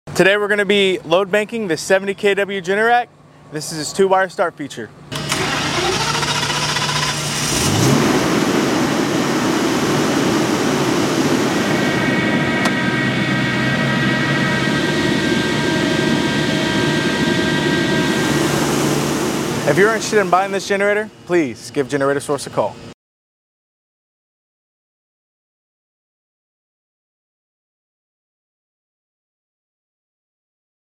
70kW Generac Natural Gas Generator sound effects free download